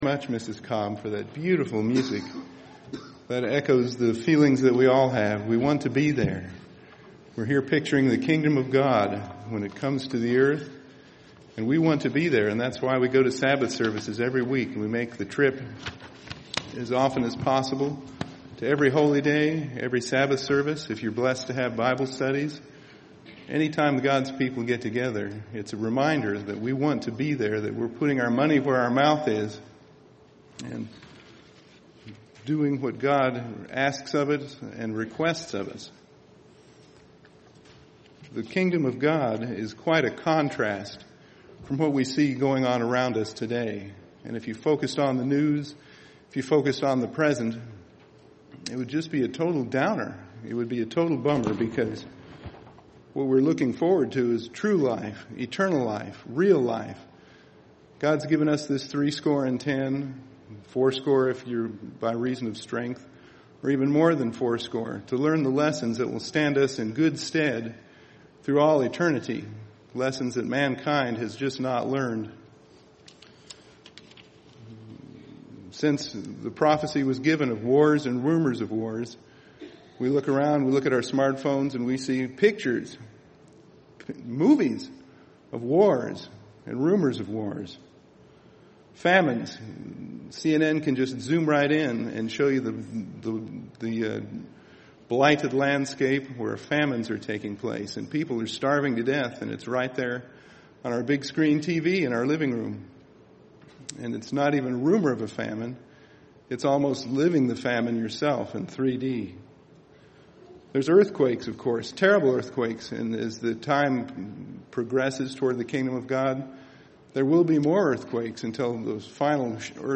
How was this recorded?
This sermon was given at the Cincinnati, Ohio 2015 Feast site.